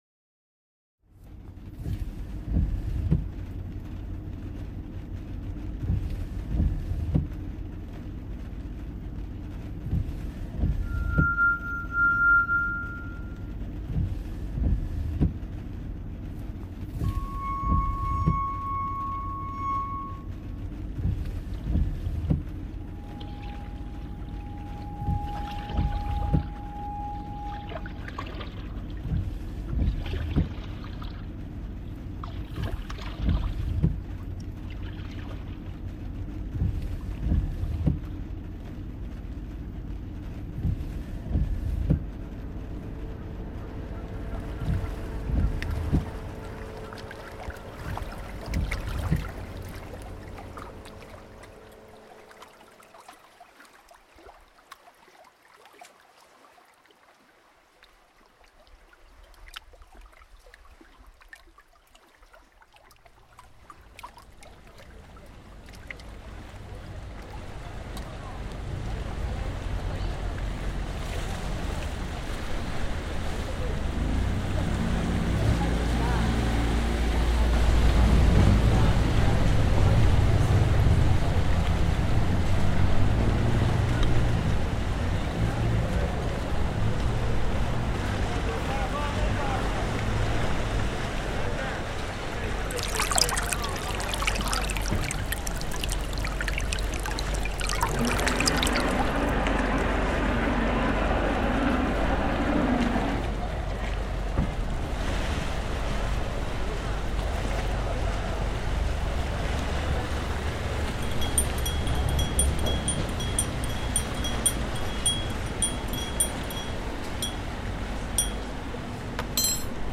added personal sound recordings from four Canadian waters